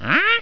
1 channel
huh.wav